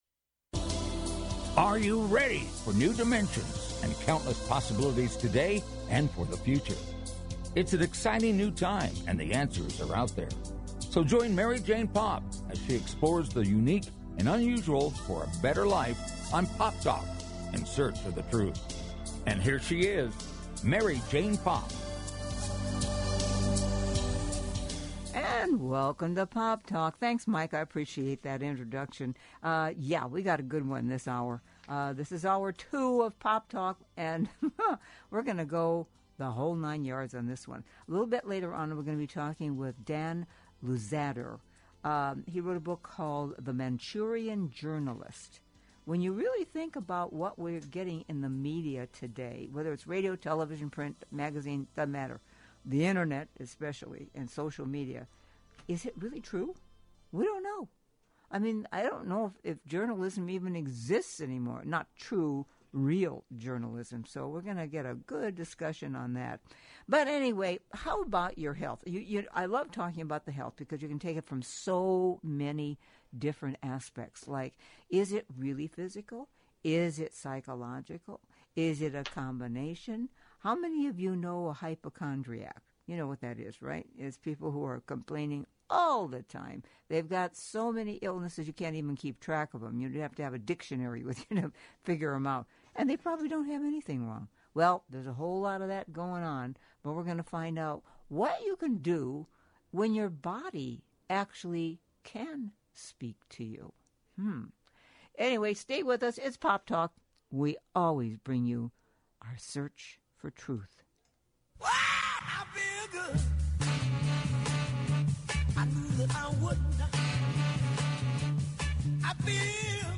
Talk Show
A fast-paced Magazine-style Show dedicated to keeping you on the cutting edge of today's hot button issues. The show is high energy, upbeat and entertaining.